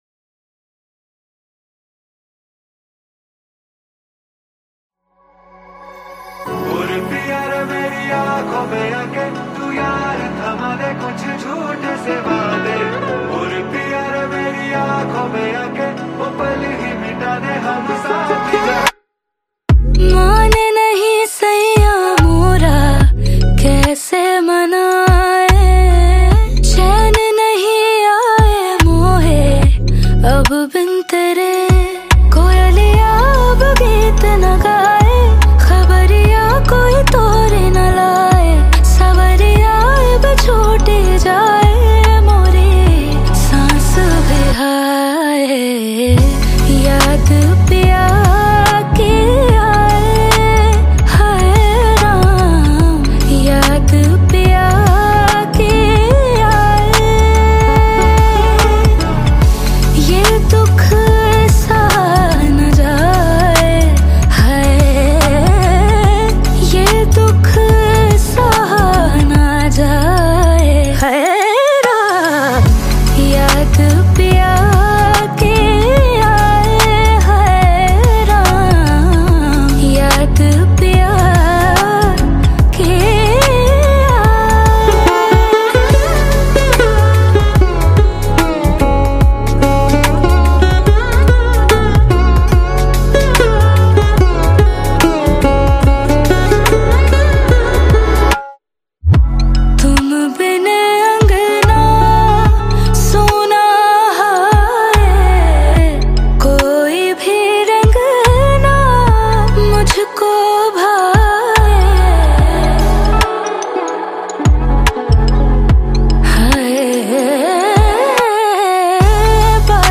modern romantic song
dreamy and slightly melancholic world
If you like slow, lyrical, and heartfelt tracks